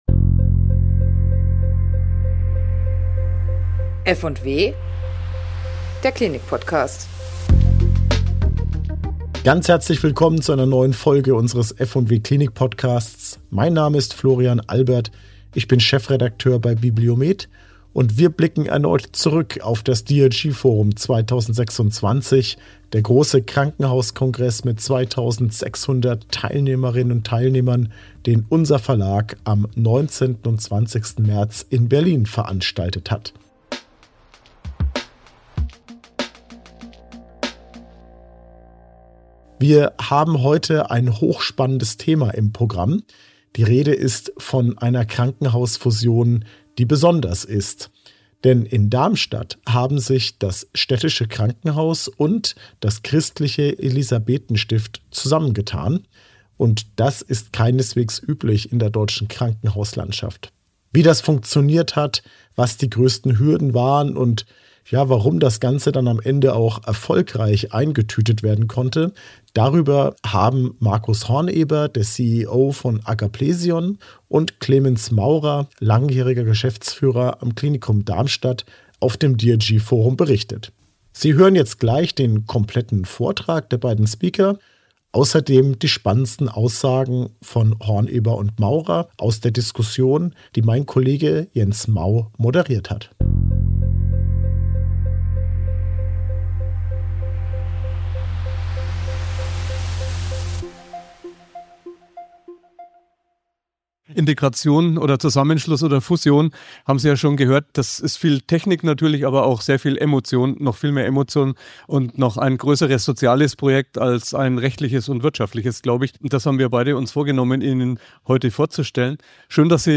Im Vortrag auf dem DRG|FORUM